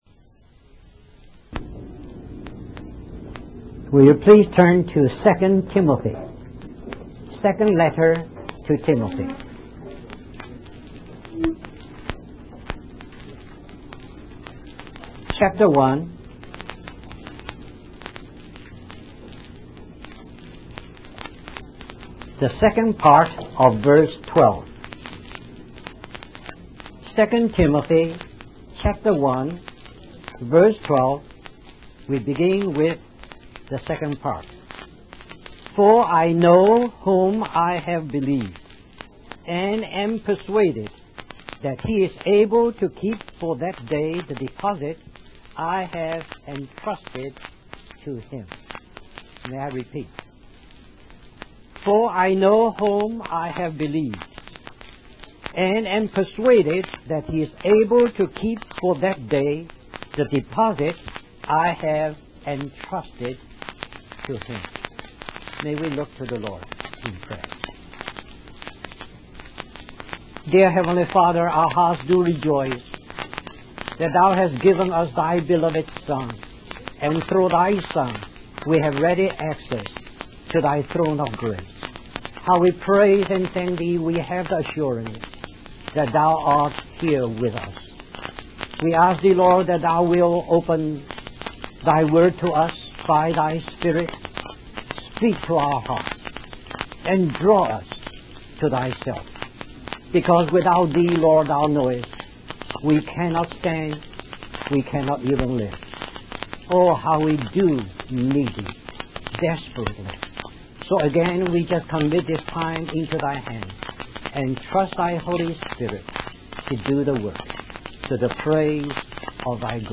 In this sermon, the speaker emphasizes the importance of committing ourselves fully to the Lord Jesus. He explains that true commitment brings peace and blessings, as we trust that we are in good hands. The speaker also highlights the significance of committing our bodies to the Lord, as He can keep them pure and blameless.